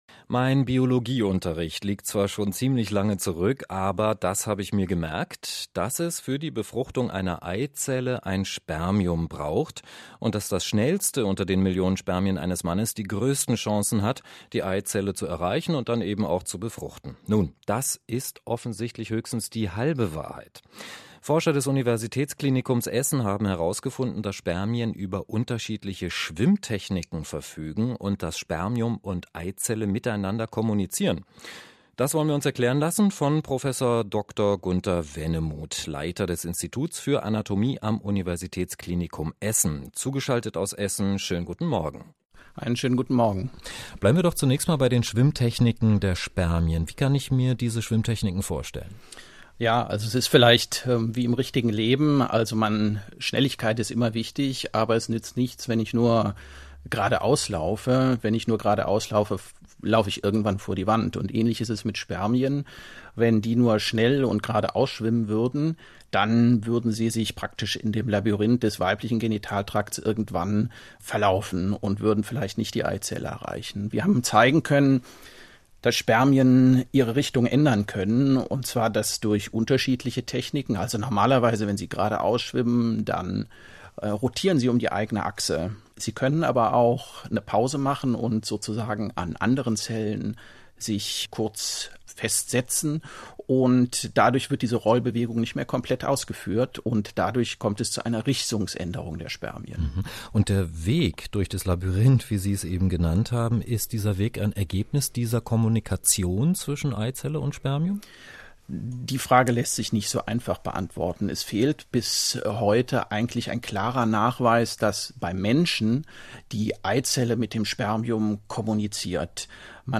Radiobeitrag aus dem RBB zu unserer Forschung: